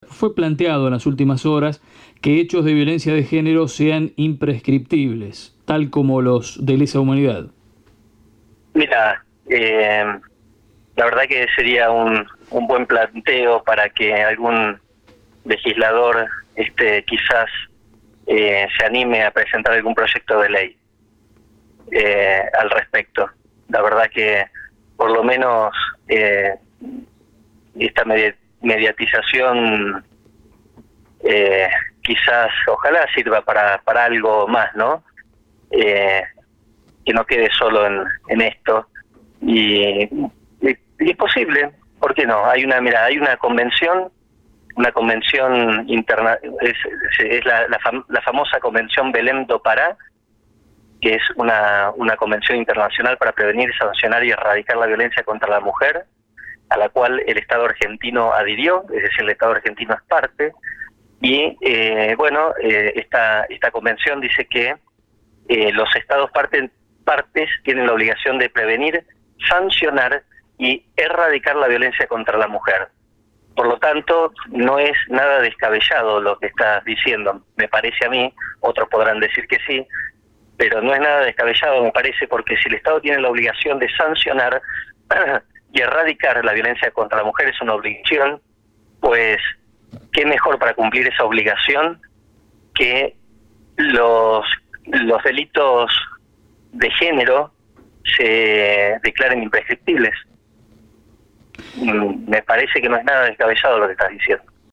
El Dr. Eduardo Maffia habló telefónicamente con Distrito Interior a las pocas horas de que fuera capturado el ameghinense sobre el que pesaba una orden nacional.